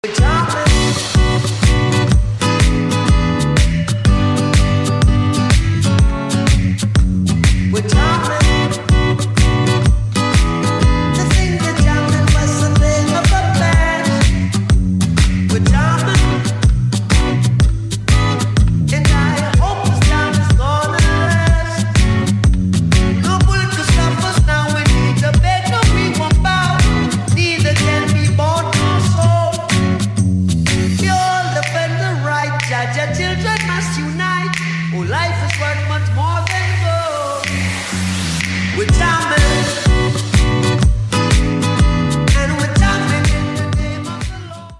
• Качество: 128, Stereo
гитара
позитивные
Reggae
guitar